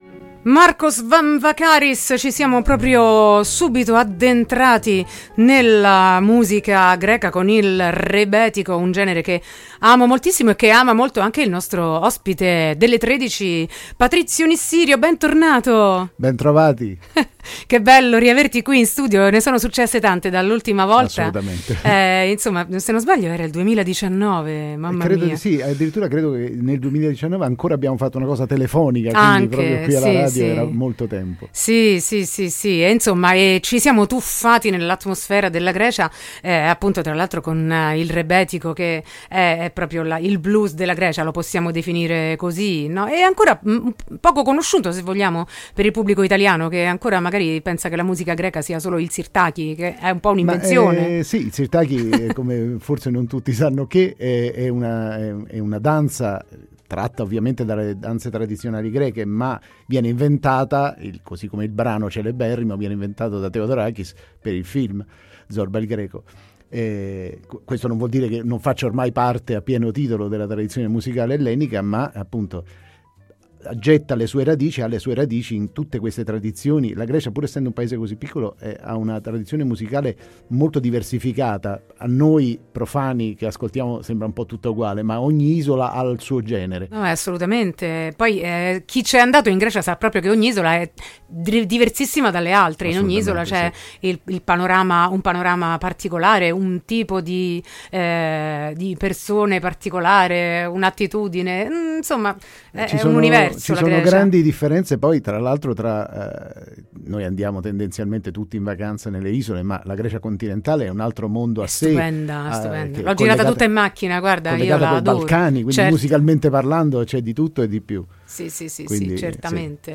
Ad Atene con Markaris: Intervista